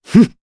Pavel-Vox_Attack2_jp_b.wav